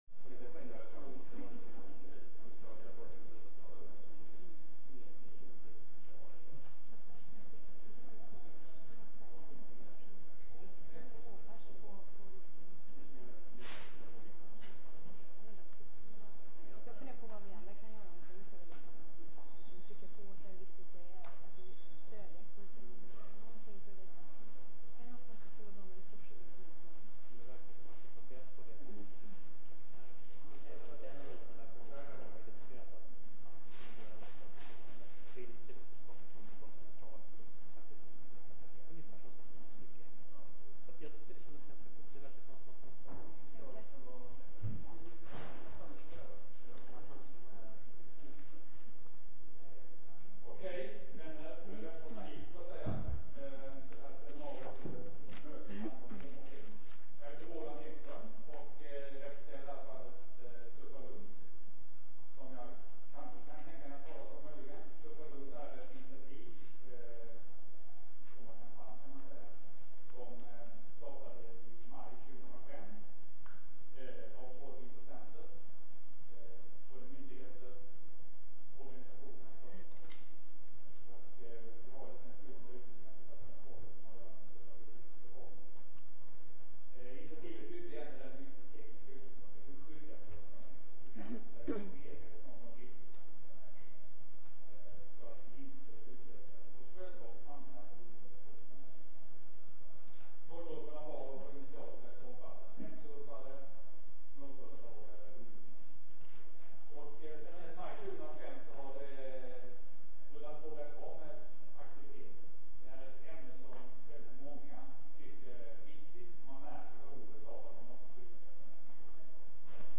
I denna rundabordsdiskussion tar vi upp situationen idag och hur n�takt�rer kan f�rhindra detta missbruk.